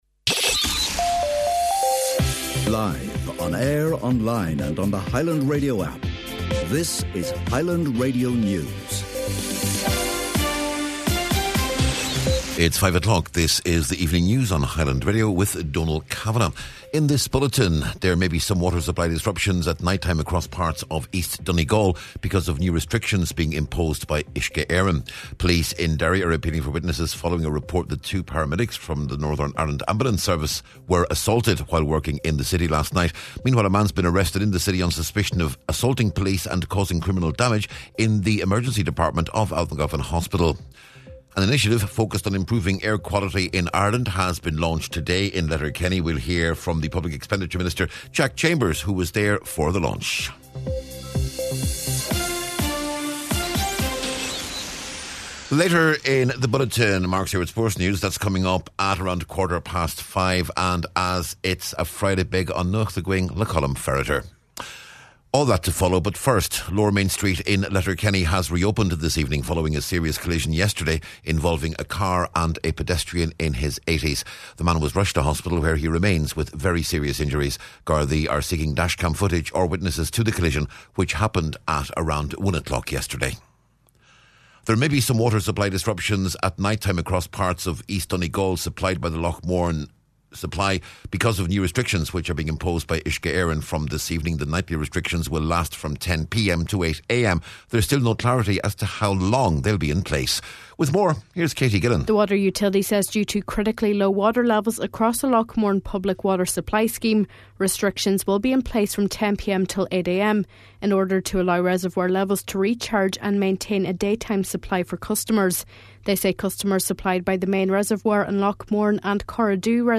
Evening News, Sport, Nuacht and Obituaries on Friday January 16th